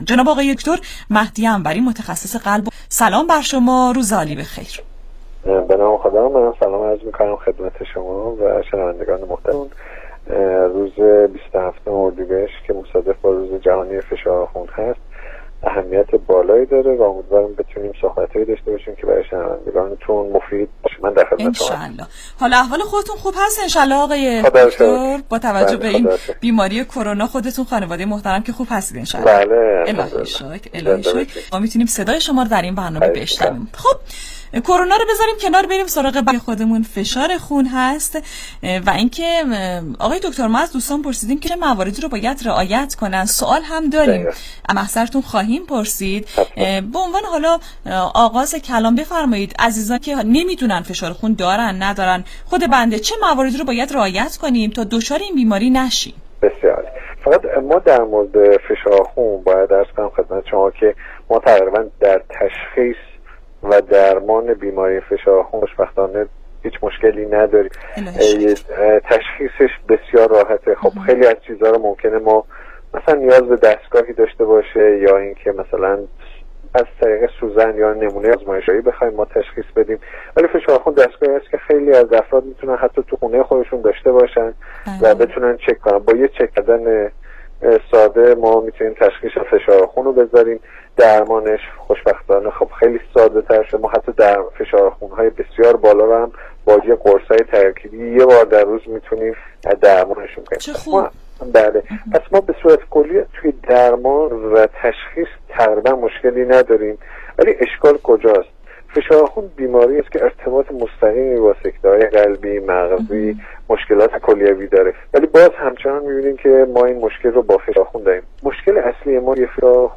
برنامه رادیویی کانون مهر با موضوع فشارخون بالا